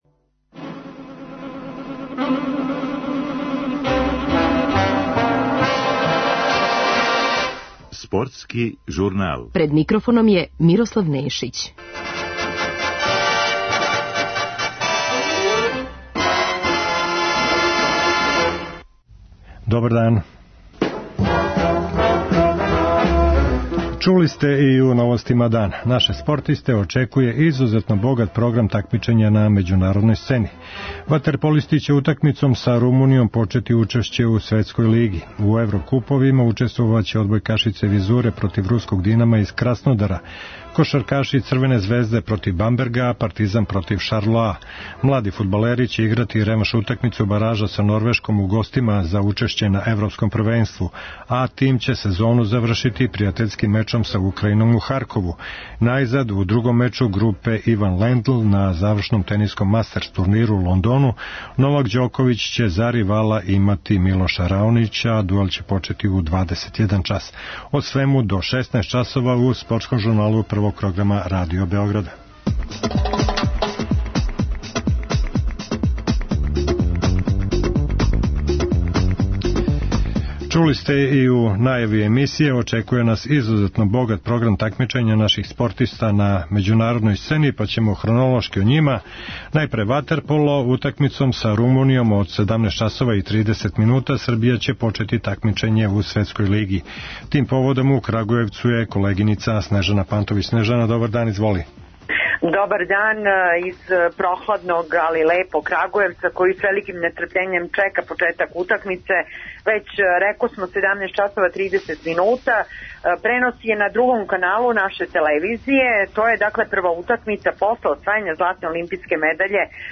Припремили смо и разговор са пролављеним кошаркашким тренером Божидаром Маљковићем о актуелностима из овог спорта.